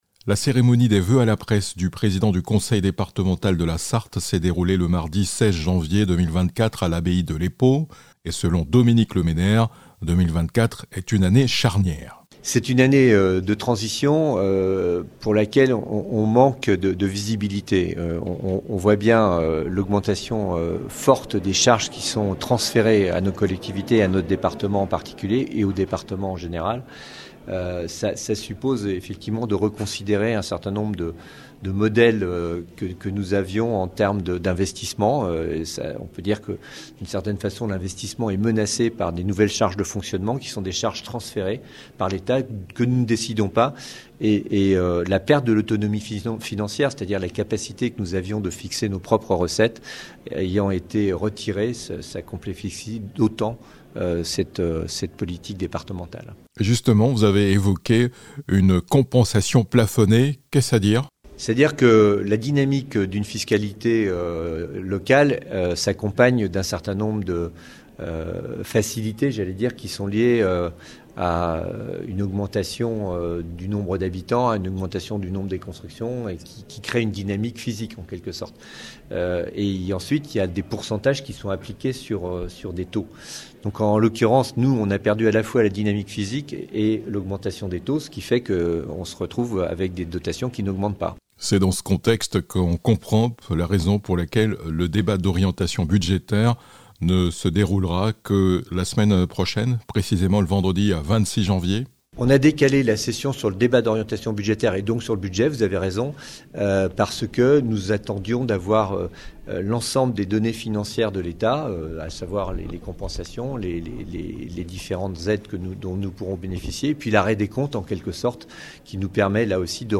L'Abbaye Royale de l'Epau a servi de cadre à la cérémonie des voeux à la presse du président du Conseil départemental de la Sarthe. Tout d'abord, Dominique Le Méner est revenu sur les grands moments de 2023, puis il a présenté les grands dossiers de 2024 qu'il a qualifiée "d'année de transition".